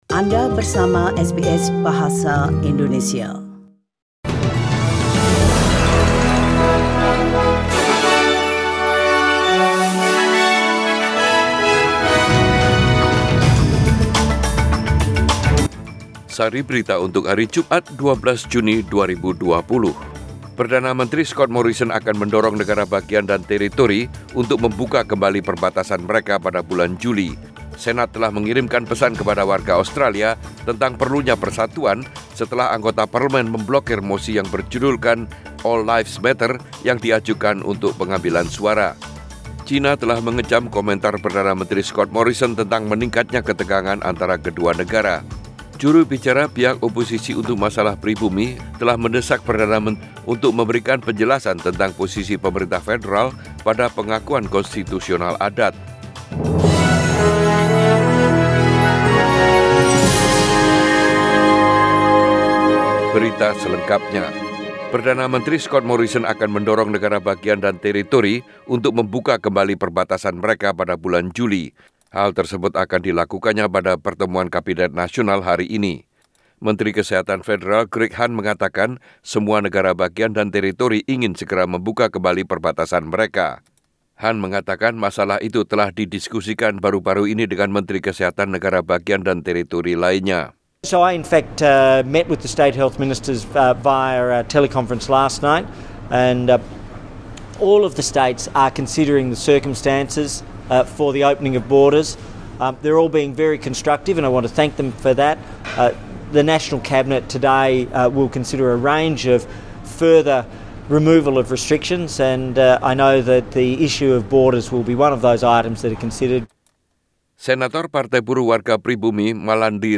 SBS Radio News in Bahasa Indonesia - 12 June 2020